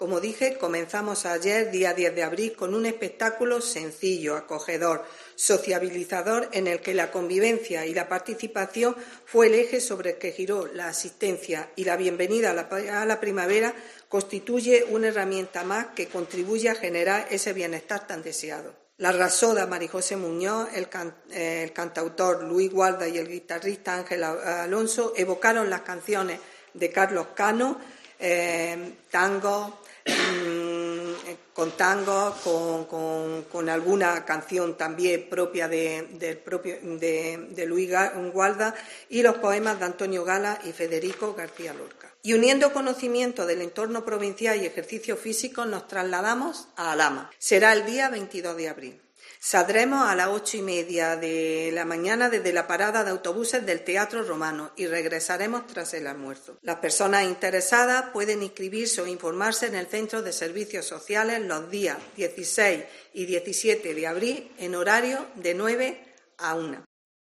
Encarni Pérez, concejal de mayores